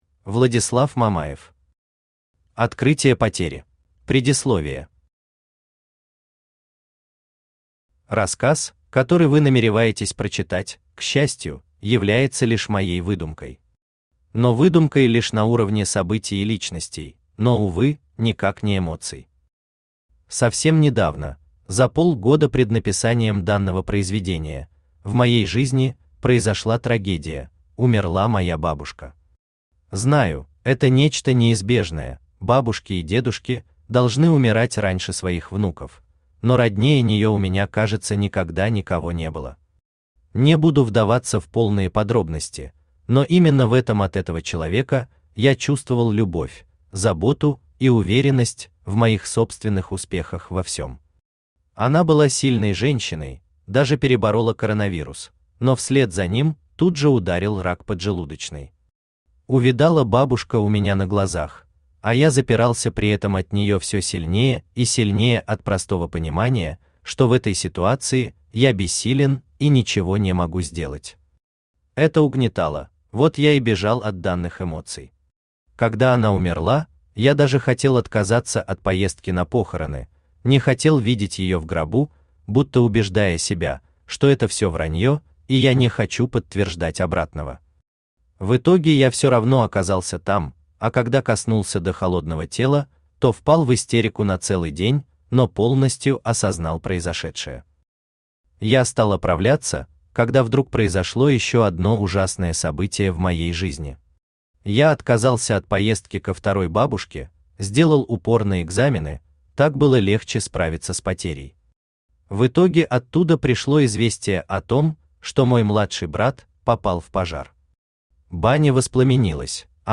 Аудиокнига Открытие потери | Библиотека аудиокниг
Aудиокнига Открытие потери Автор Владислав Андреевич Мамаев Читает аудиокнигу Авточтец ЛитРес.